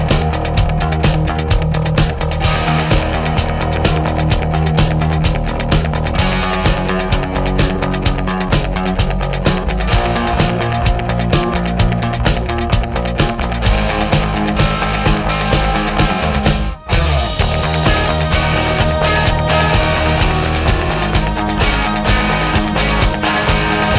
Minus Main Guitars Rock 4:38 Buy £1.50